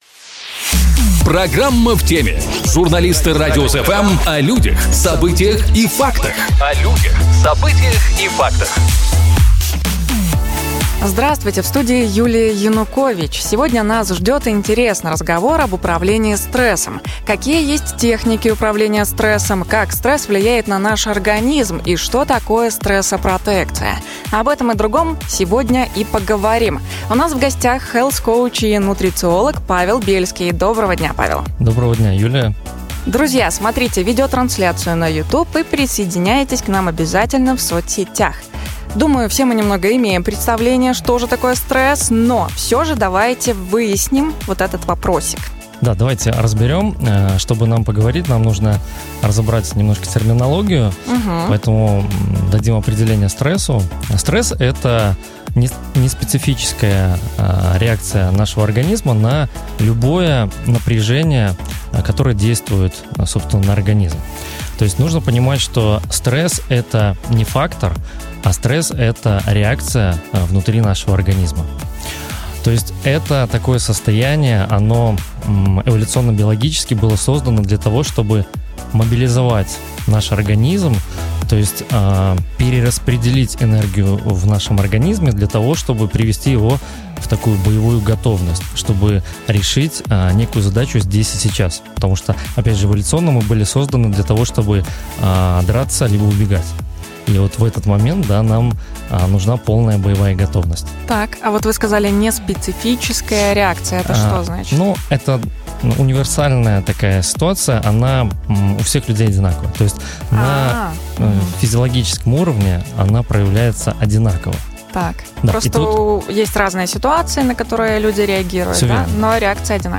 Сегодня - интересный разговор об управлении стрессом.